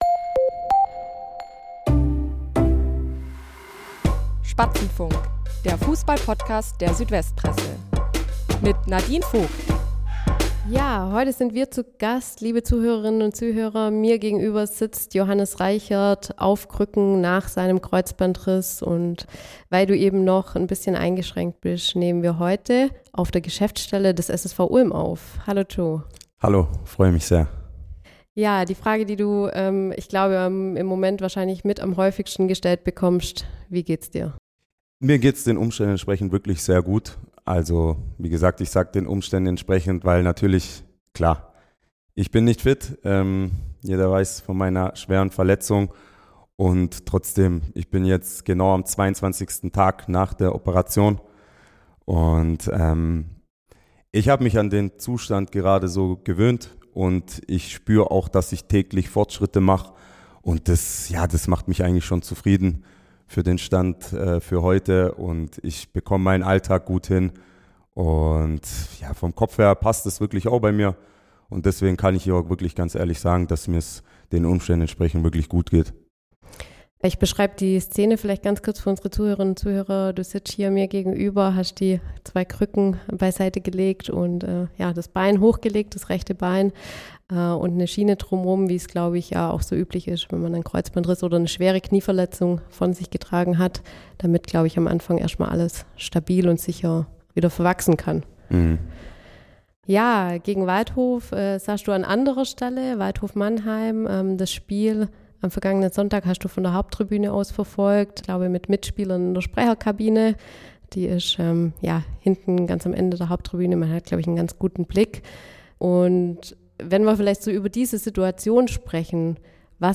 Interview - Der verletzte Kapitän ~ Spatzenfunk – Der Fußball-Podcast der Südwest Presse Podcast